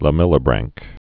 (lə-mĕlə-brănk)